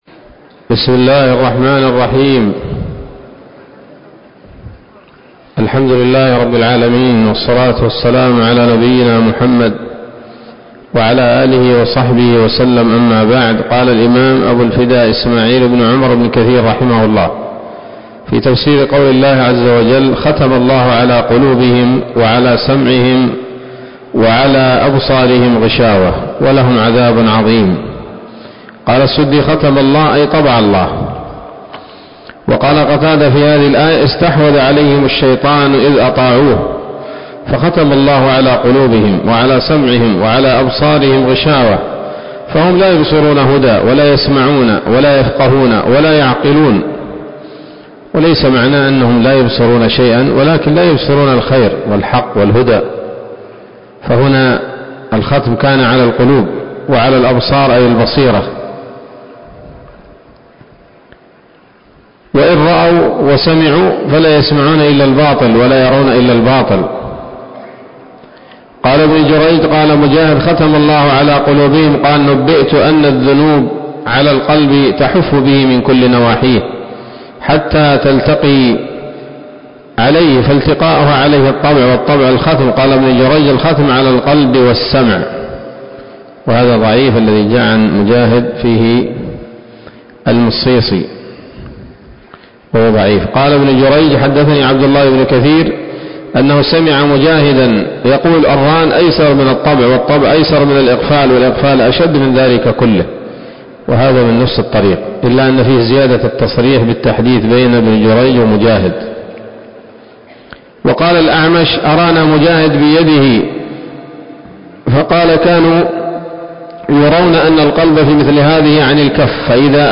الدرس الثالث عشر من سورة البقرة من تفسير ابن كثير رحمه الله تعالى